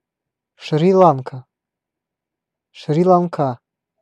Ääntäminen
US : IPA : [ʃɹiː ˈlɑːŋ.kə]